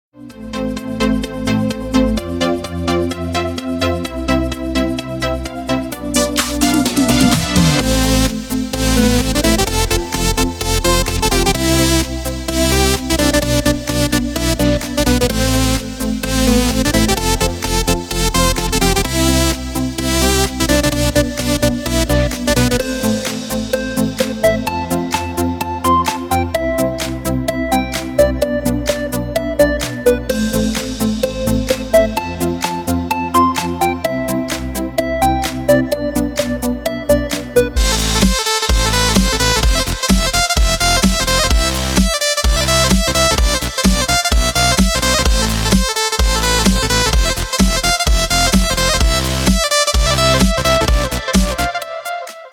• Качество: 320, Stereo
без слов
club